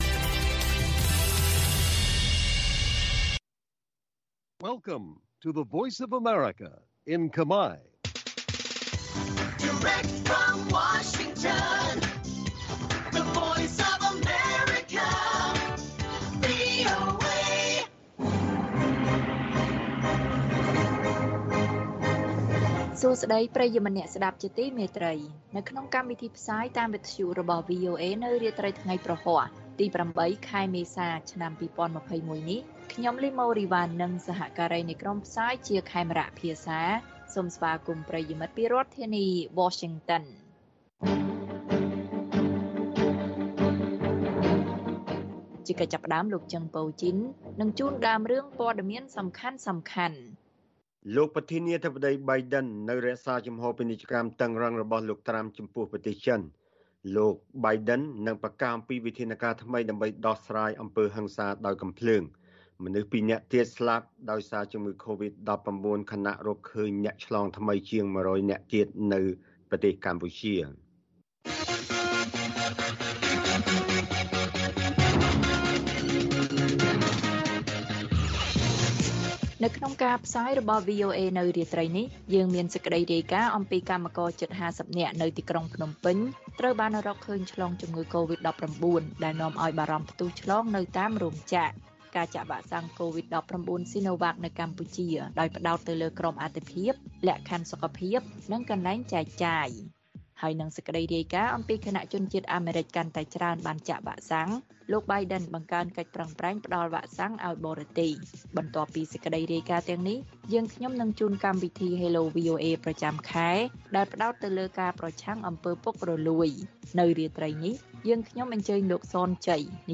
កិច្ចសន្ទនារវាងអ្នកយកព័ត៌មានឲ្យ VOA អំពីការឆ្លងរាលដាលនៃវីរុសកូរ៉ូណាក្នុងរោងចក្រកាត់ដេរនិងនៅរាជធានីភ្នំពេញ។